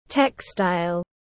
Vải dệt tiếng anh gọi là textile, phiên âm tiếng anh đọc là /ˈtek.staɪl/.